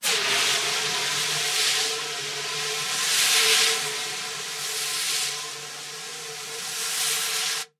Звуки огнетушителя
На этой странице собраны звуки огнетушителя: шипение пены, нажатие рычага, распыление состава.
Шипящий звук распыления пены из огнетушителя